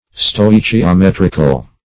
\Stoi`chi*o*met"ric*al\, a.